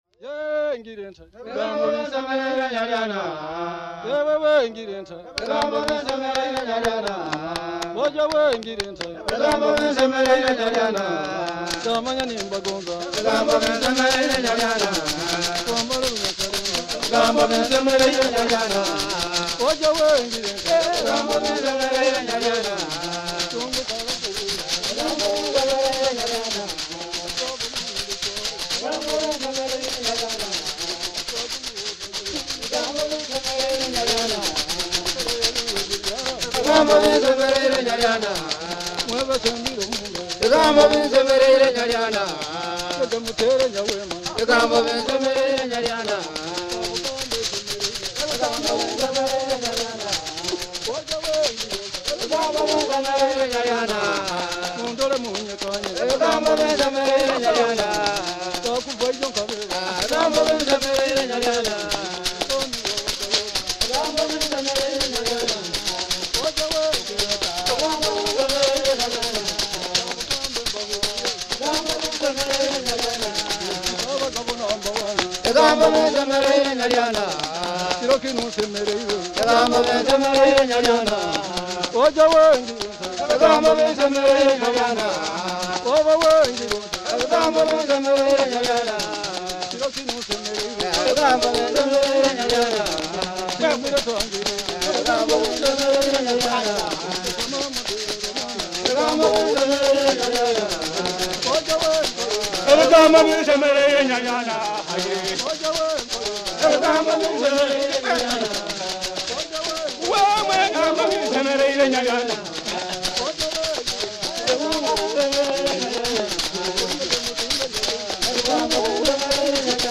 Folk music
Field recordings
sound recording-musical
"We were pleased with your words" they reply in chorus. The answer to an invitation to a drinking party.
Drinking song, with Ebinyege leg rattles and clapping.